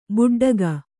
♪ buḍḍaga